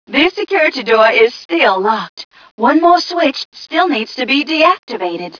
mission_voice_t7ca014.wav